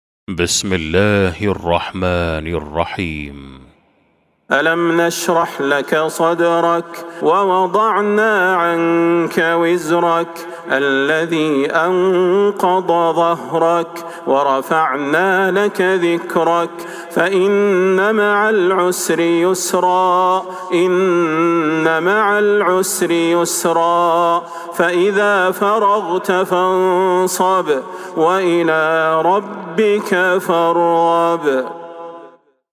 سورة الشرح Surat Ash-Sharh > مصحف تراويح الحرم النبوي عام 1443هـ > المصحف - تلاوات الحرمين